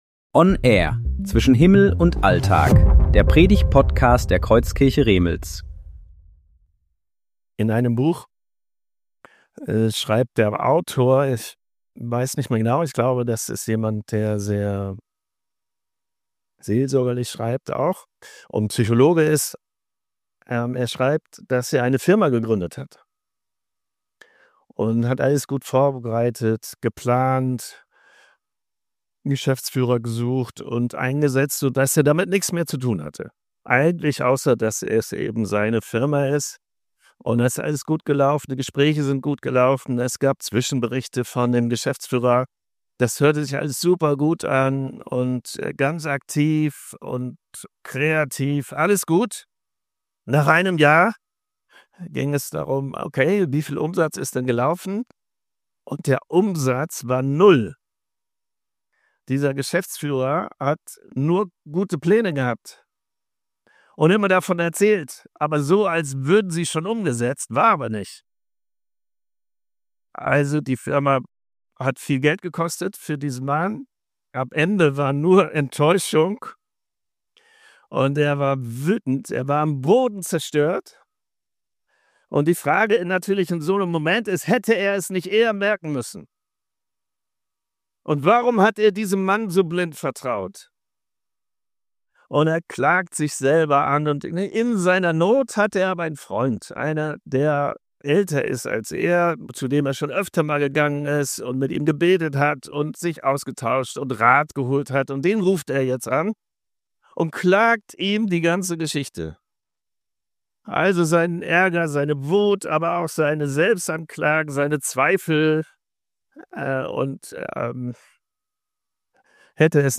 Predigtserie: Gottesdienst